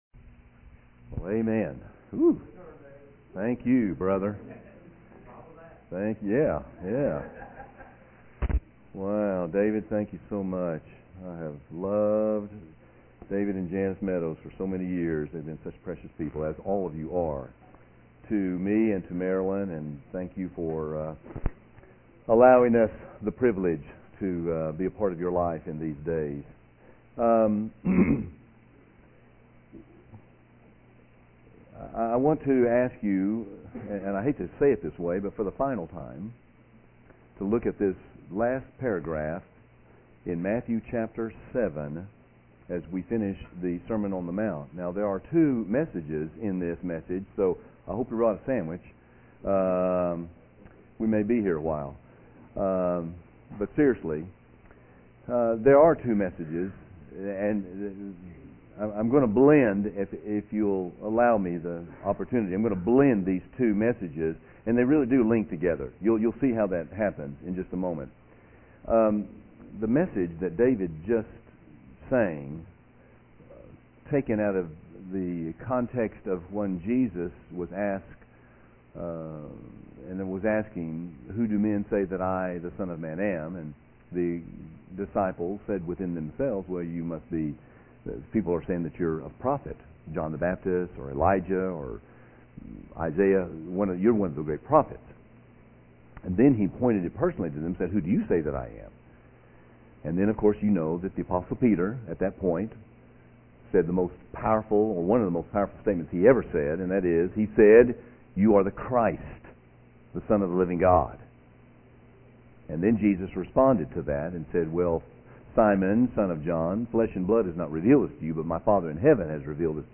A Study Through The Sermon On The Mount Our Ultimate Choice